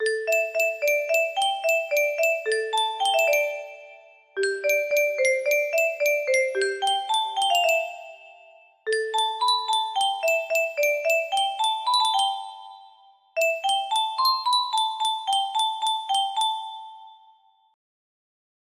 Unknown Artist - ccc music box melody
Wow! It seems like this melody can be played offline on a 15 note paper strip music box!